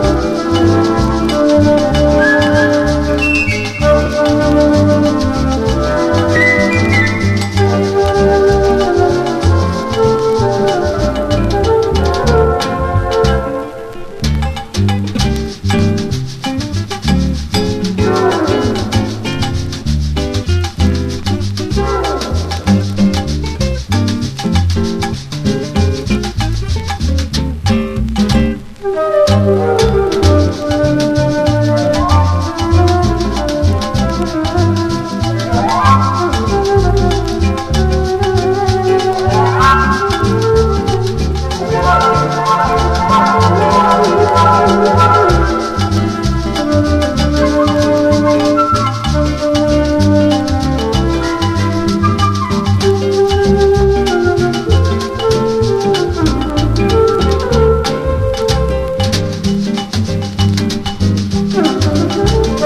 PERCUSSION / BONGO / INSTRO / LATIN
パーカッション・インスト！